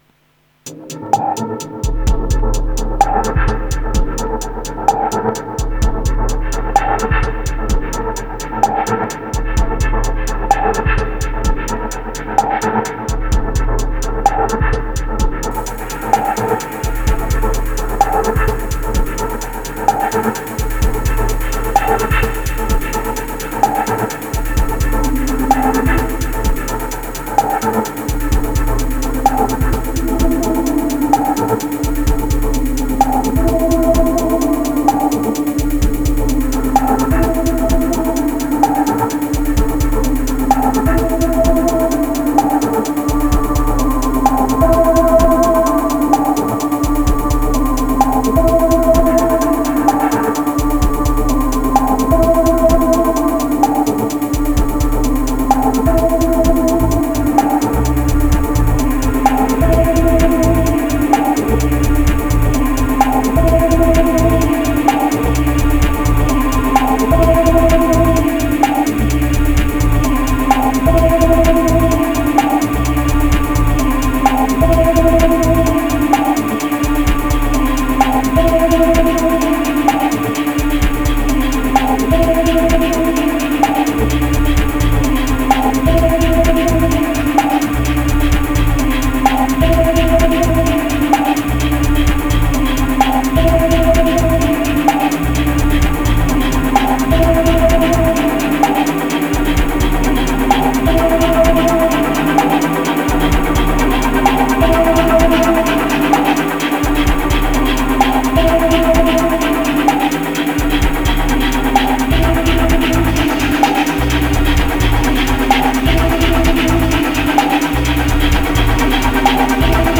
505📈 - 67%🤔 - 128BPM🔊 - 2025-08-15📅 - 465🌟